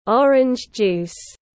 Nước cam ép tiếng anh gọi là orange juice, phiên âm tiếng anh đọc là /ˈɒr.ɪndʒ ˌdʒuːs/
Orange juice /ˈɒr.ɪndʒ ˌdʒuːs/